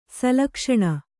♪ salakṣaṇa